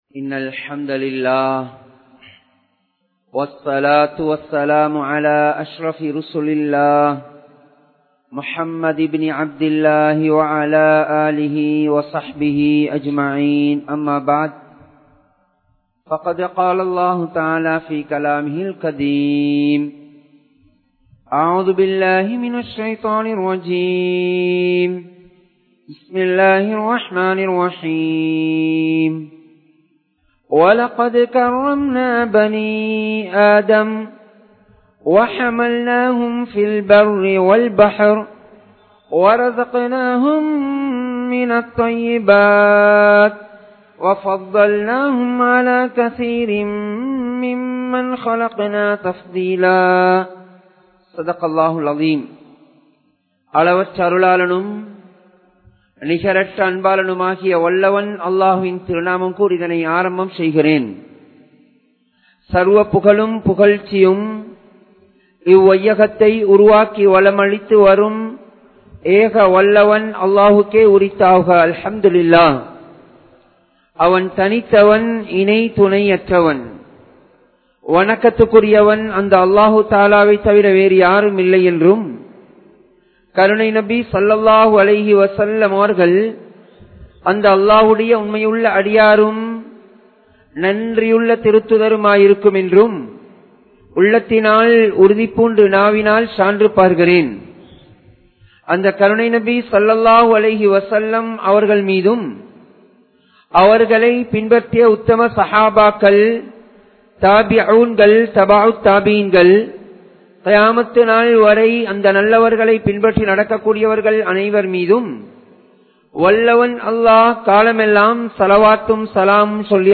Mathuvin Vilaivuhal (மதுவின் விளைவுகள்) | Audio Bayans | All Ceylon Muslim Youth Community | Addalaichenai
Ar Rahmath Jumua Masjidh